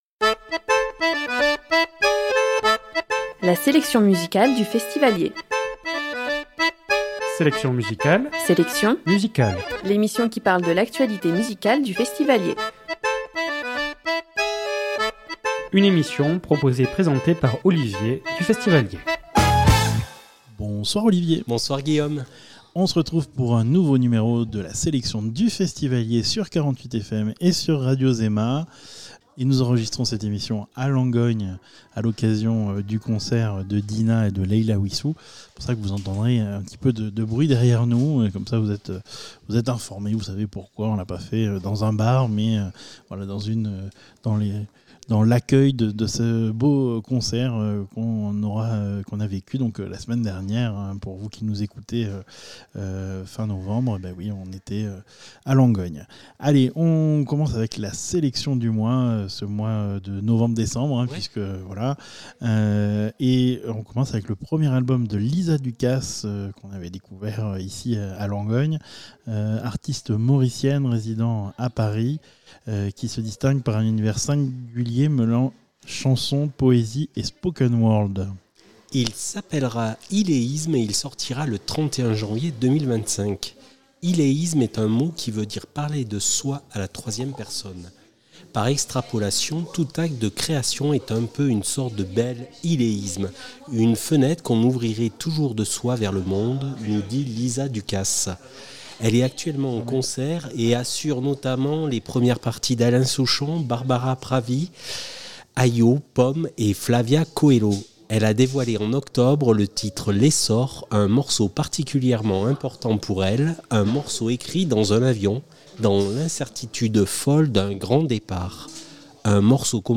Emission du vendredi 29 novembre 2024 à 19hRediffusion le dimanche suivant à 21h